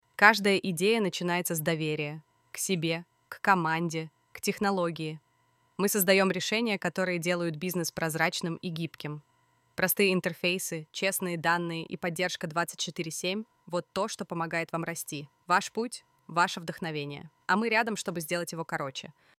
Озвучка и дикторская работа
Сеть Диктор 2.0 создала чистую, довольно выразительную озвучку с органичной фразировкой и подходящими контексту интонациями. Хотя, на наш взгляд, легкий ИИ-след в звучании все-таки присутствует.